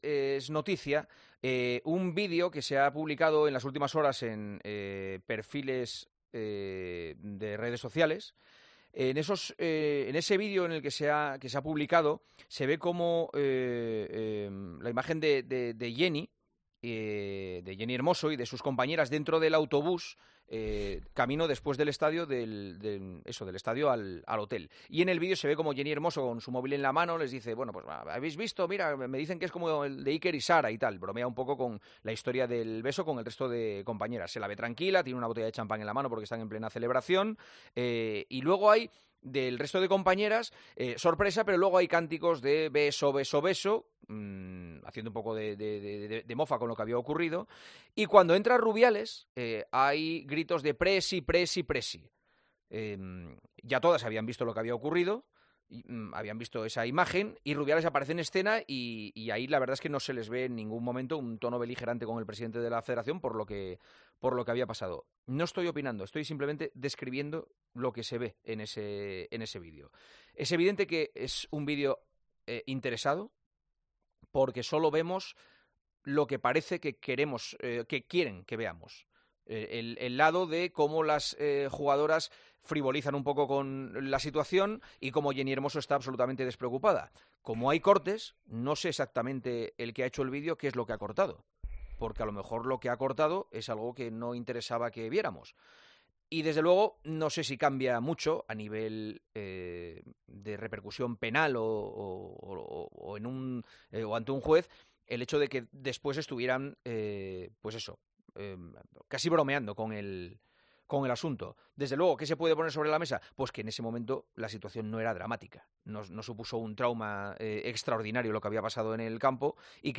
Los tertulianos de El Partidazo de COPE opinan sobre este nuevo vídeo